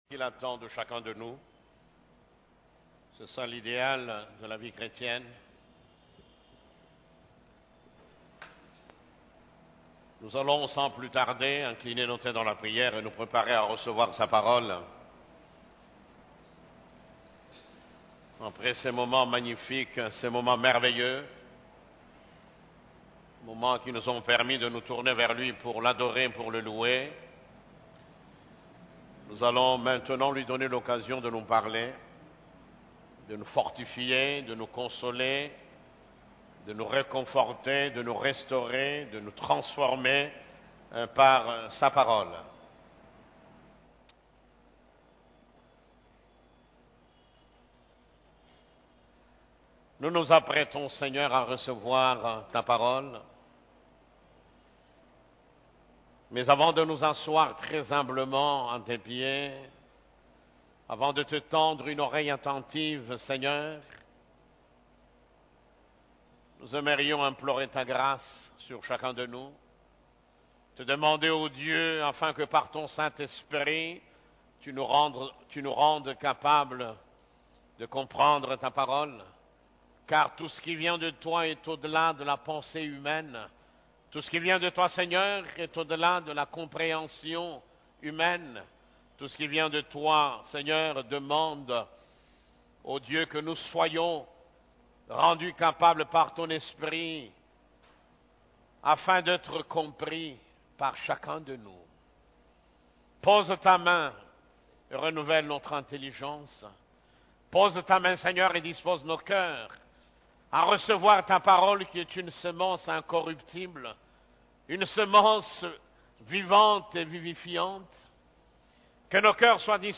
Culte du Dimanche